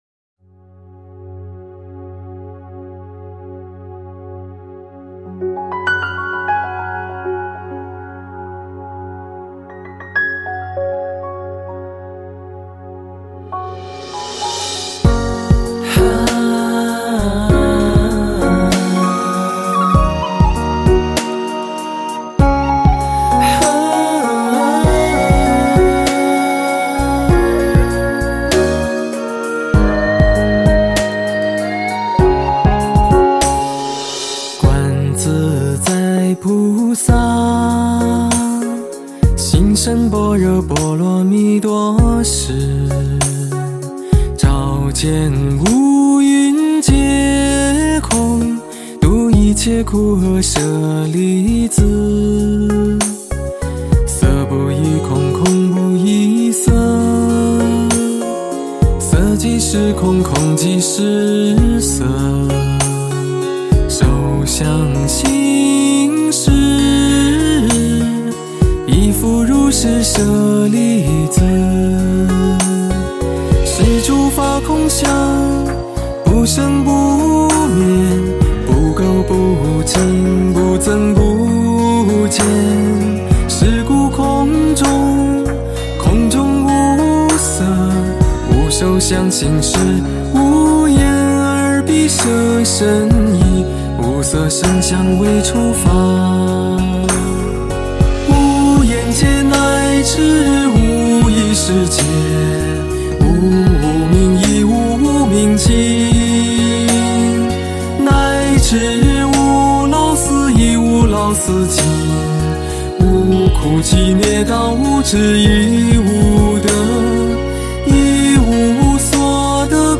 诵经
佛音 诵经 佛教音乐 返回列表 上一篇： 心经 下一篇： 大悲咒（唱诵） 相关文章 八十八佛忏悔文--未知 八十八佛忏悔文--未知...